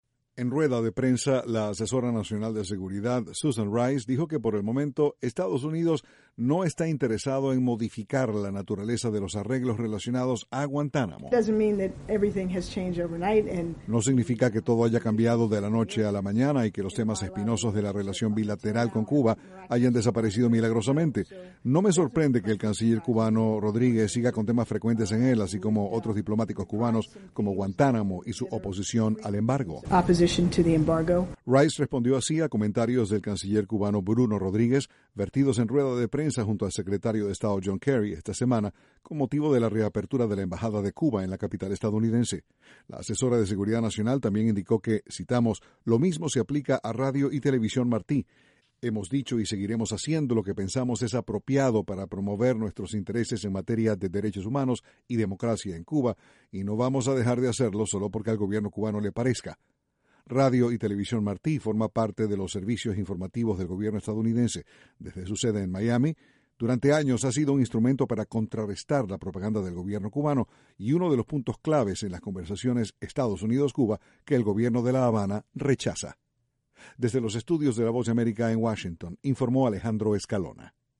La Asesora de Seguridad Nacional de Estados Unidos, Susan Rice, dijo que EEUU seguirá promoviendo los derechos humanos en Cuba aunque al gobierno de La Habana no le guste. Desde la Voz de América, Washington, informa